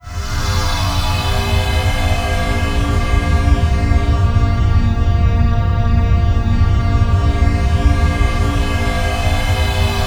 Index of /90_sSampleCDs/E-MU Producer Series Vol. 3 – Hollywood Sound Effects/Science Fiction/Brainstem